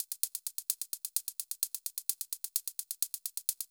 CLF Beat - Mix 12.wav